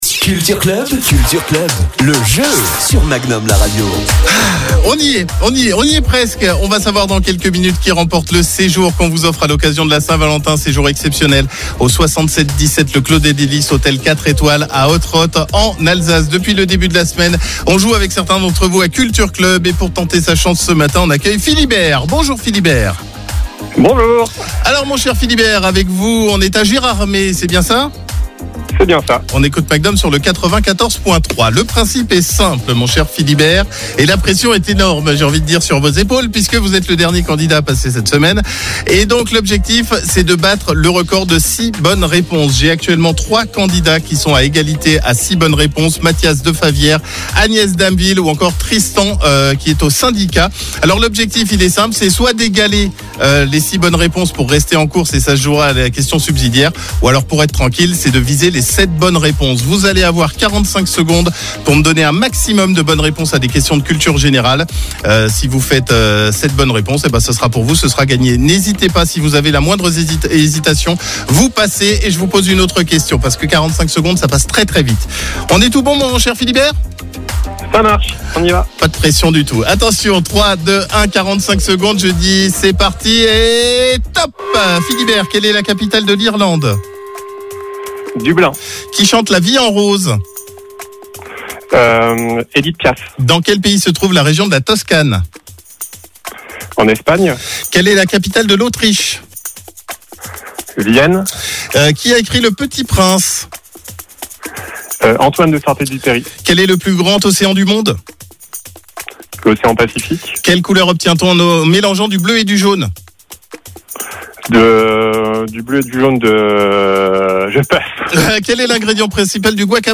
Le candidat devra donner un maximum de bonnes réponses à des questions de culture générale (vie quotidienne, people, cinéma, musique, histoire, géographie etc...).